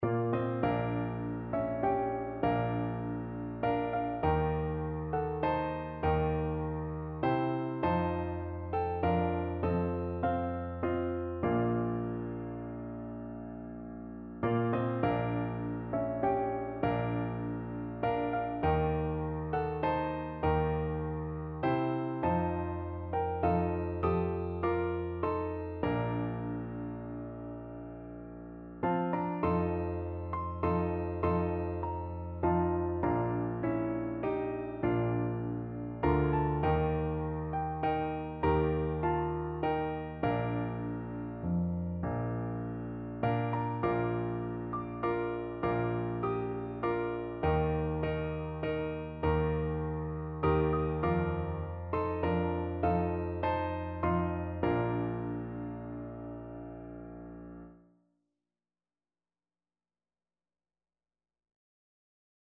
Free Sheet music for Piano Four Hands (Piano Duet)
6/8 (View more 6/8 Music)
Classical (View more Classical Piano Duet Music)